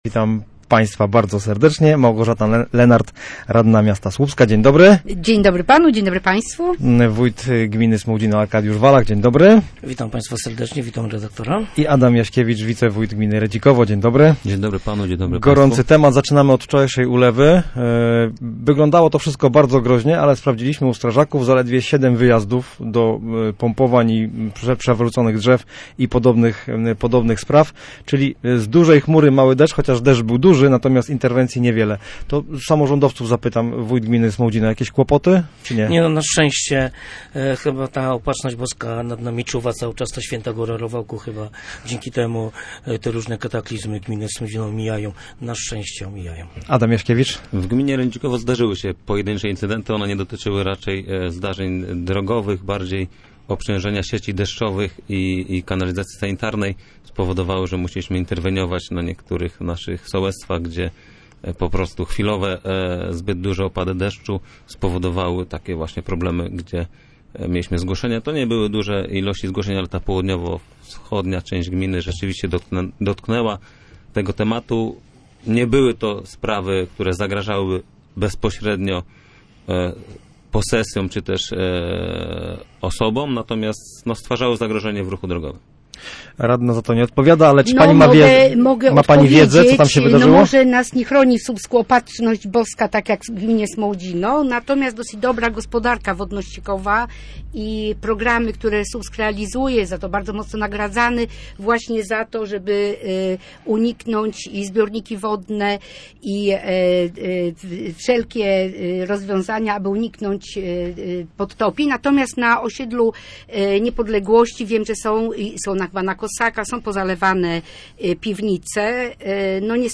W Studiu Słupsk lokalni samorządowcy komentowali projekt rozporządzenia Rady Ministrów w sprawie zmiany granic Słupska. Zaproszenie do programu przyjęli: radna klubu Łączy Nas Słupsk Małgorzata Lenart, wójt gminy Smołdzino Arkadiusz Walach oraz wicewójt gminy Redzikowo Adam Jaśkiewicz.